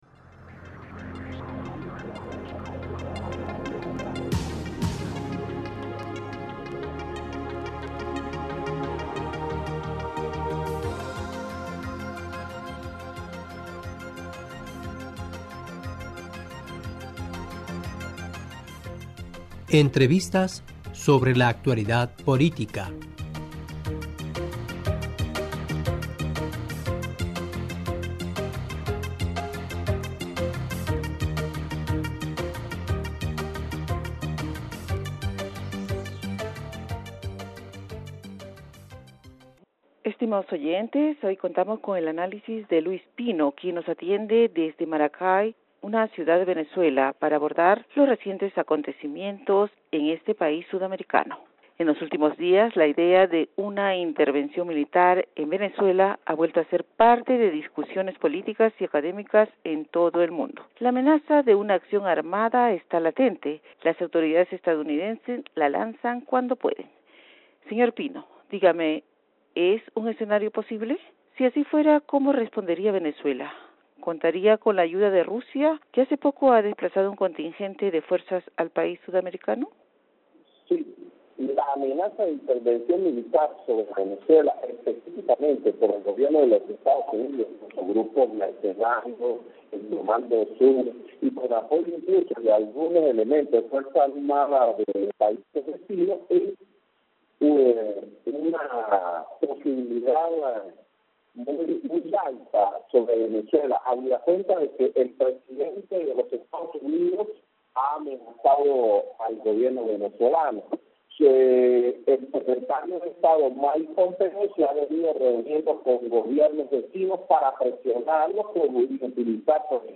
Entrevistadora (E)